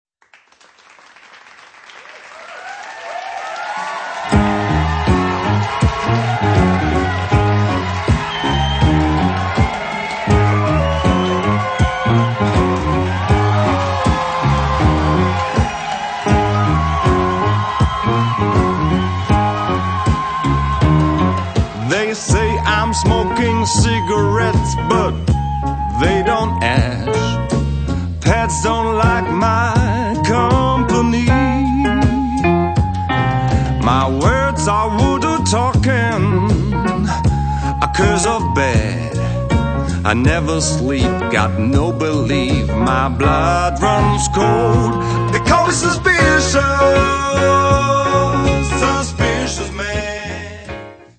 Das klingt doch nach tiefsten Siebziger Jahren!